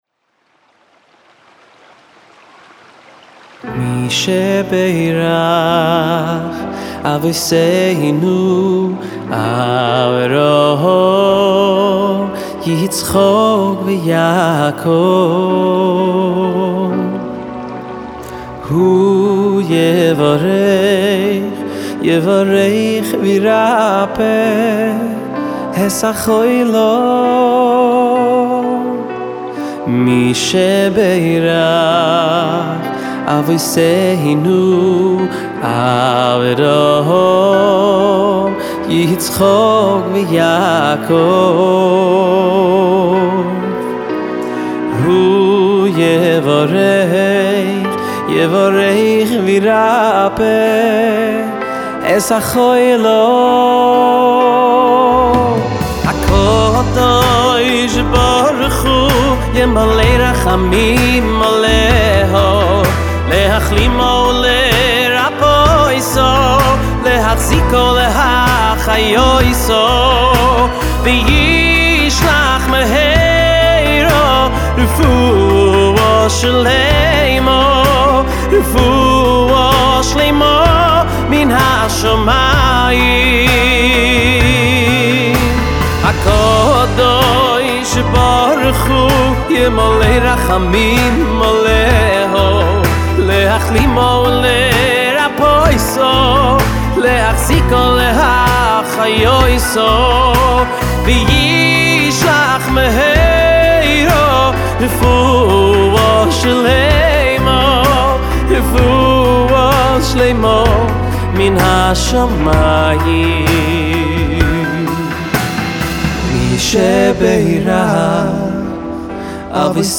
הזמר